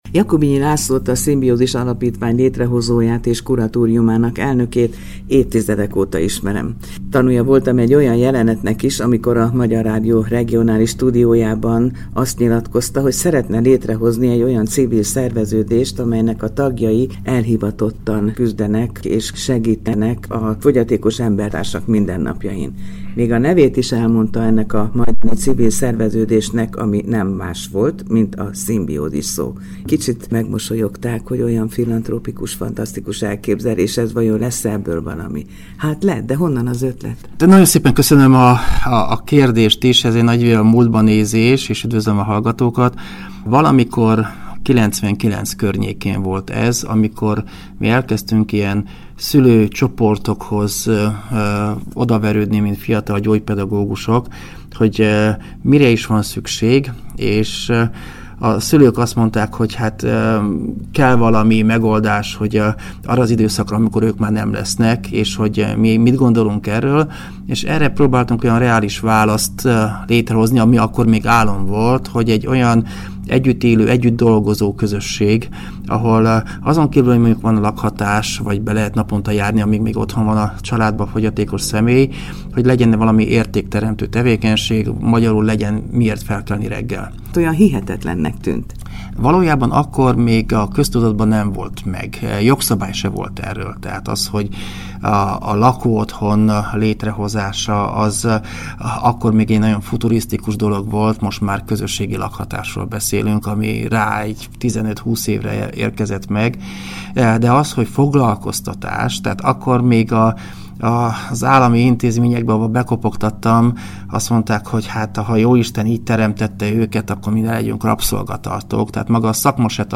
beszélgetett a Szimbiózis Alapítvány Baráthegyi Majorságában az újabb elismerés kapcsán.